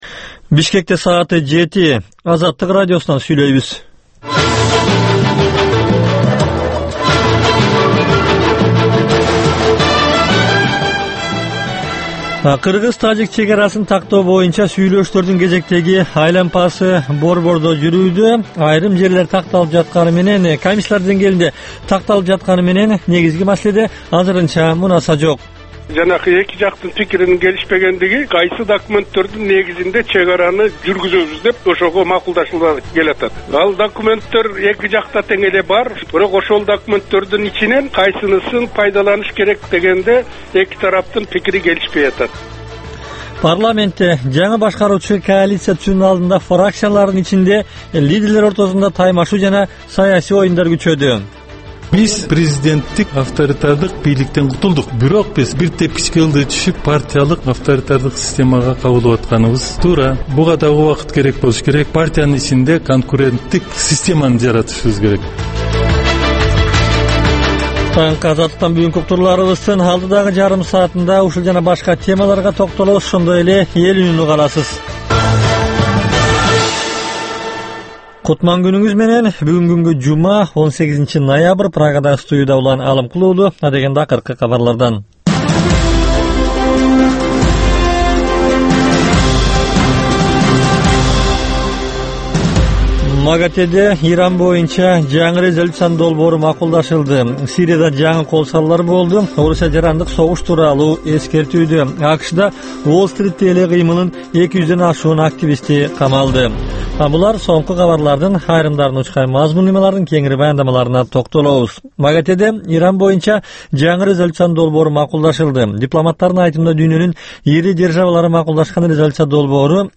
Таңкы 7деги кабарлар
"Азаттык үналгысынын" күндөлүк кабарлар топтому Ала-Тоодогу, Борбордук Азиядагы жана дүйнөдөгү эң соңку жаңылыктардан турат. Кабарлардын бул топтому «Азаттык үналгысынын» оригиналдуу берүүсү обого чыккан сааттардын алгачкы беш мүнөтүндө сунушталат.